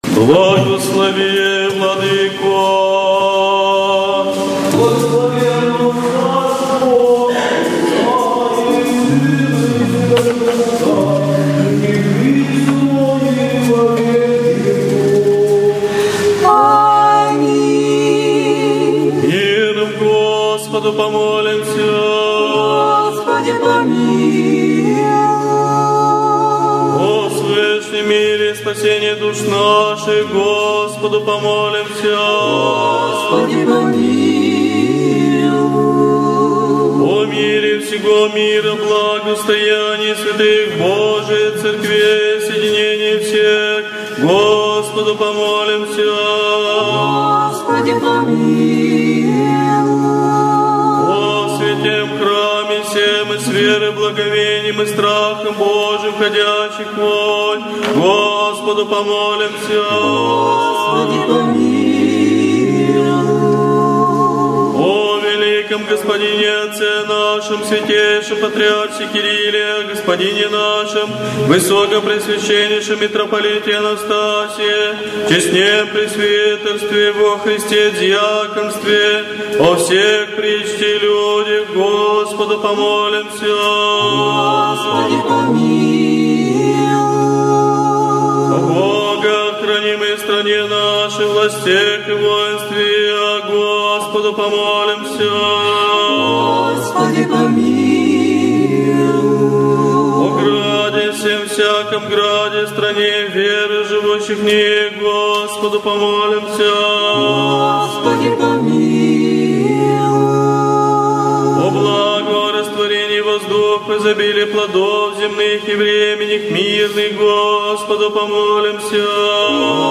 4 декабря Свято-Вознесенский собор собрал множество людей, и детей, и взрослых по случаю одного из самых почитаемых православных праздников – Введение во храм Пресвятой Богородицы.
Божественная литургия в день праздника Введения Пресвятой Богородицы